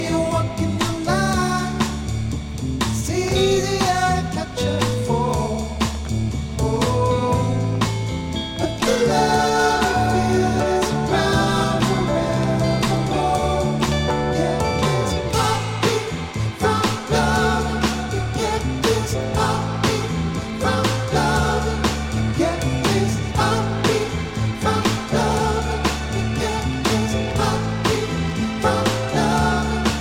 Funk, Psychedelic, Soul